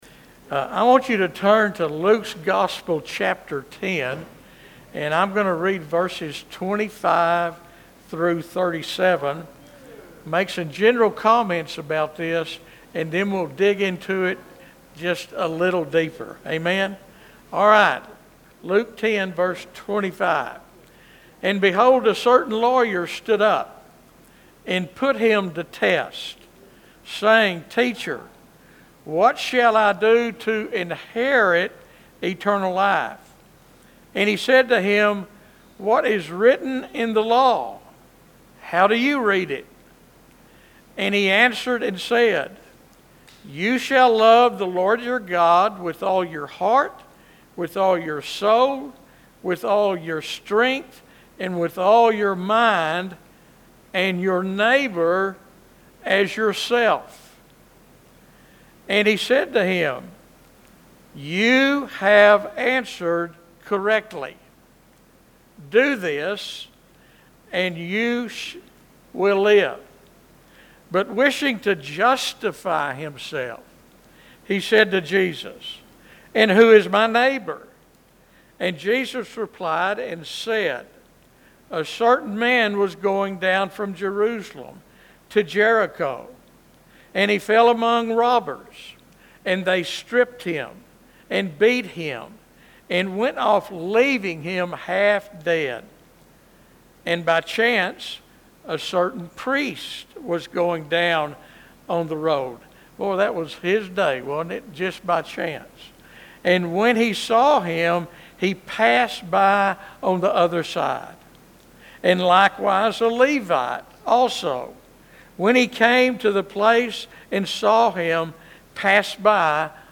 This week's sermon explores the well-known parable of the Good Samaritan, found in Luke 10:25–37, but challenges the common understanding of it as merely a story about kindness or doing good deeds.